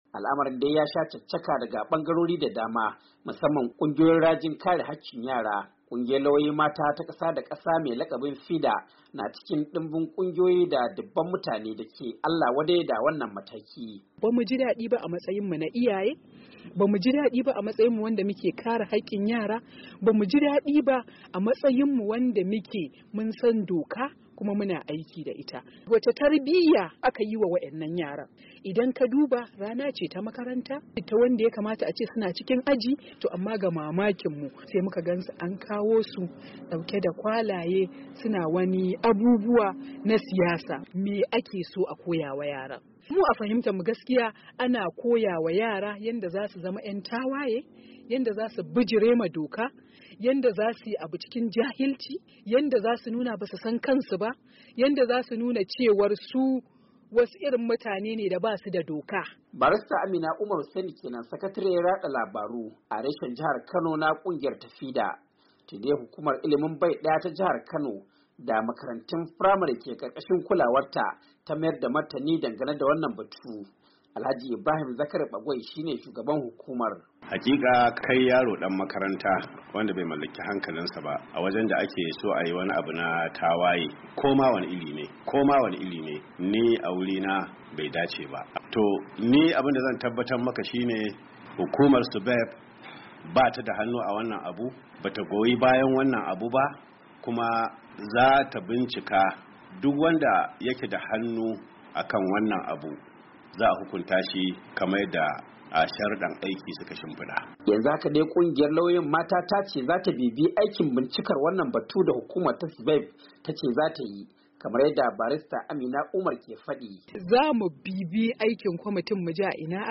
Daga jihar Kano ga rahoton